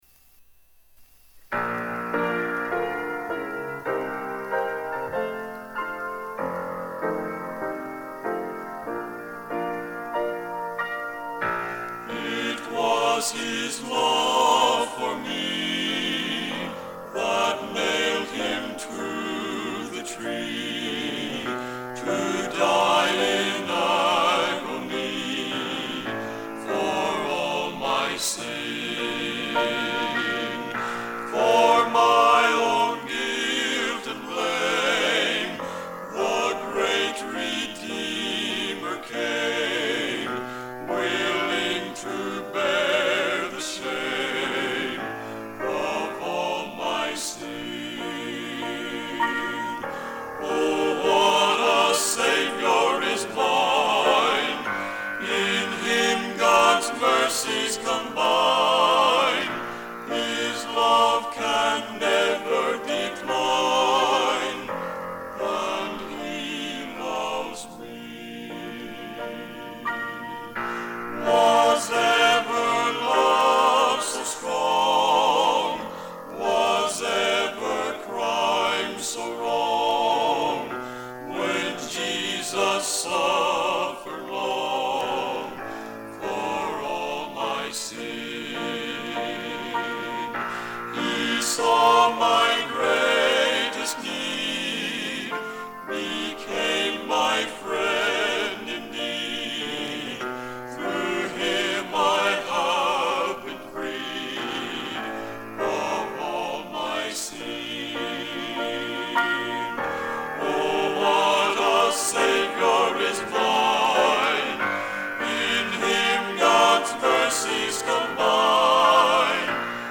vocal members